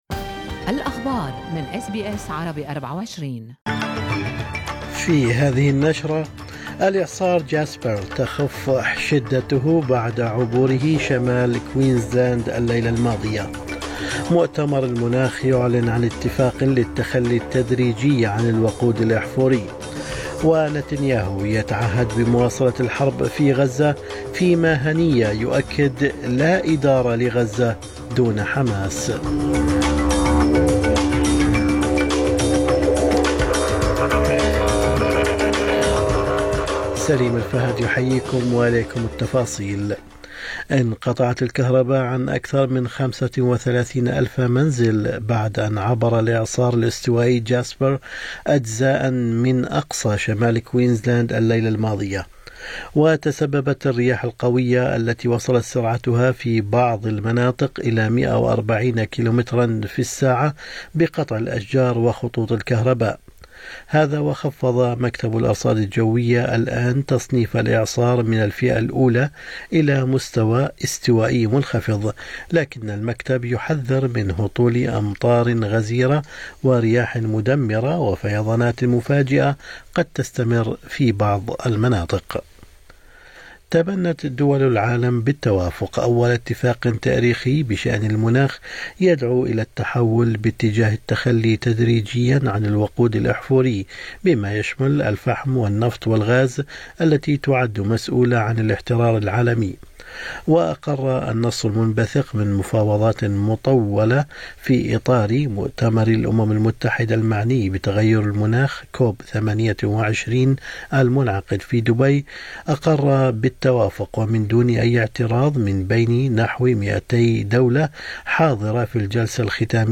نشرة أخبار الصباح 14/12/2023